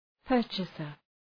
Προφορά
{‘pɜ:rtʃəsər}